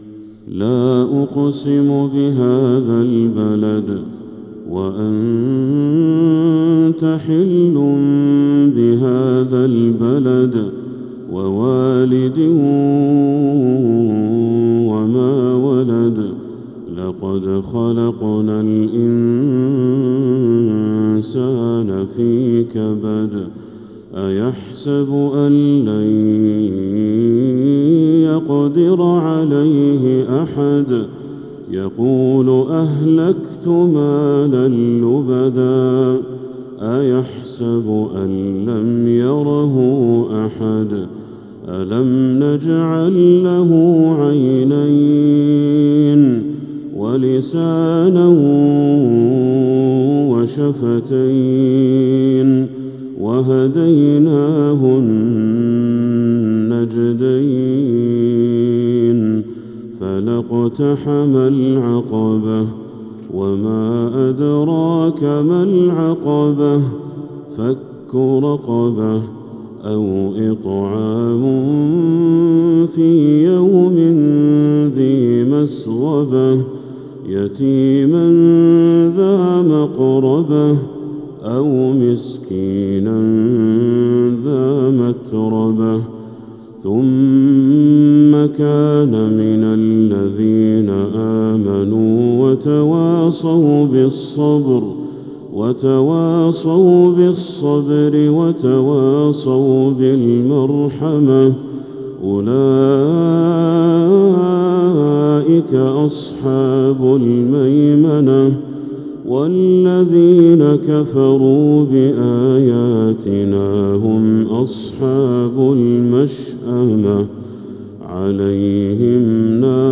سورة البلد كاملة | ذو القعدة 1446هـ > السور المكتملة للشيخ بدر التركي من الحرم المكي 🕋 > السور المكتملة 🕋 > المزيد - تلاوات الحرمين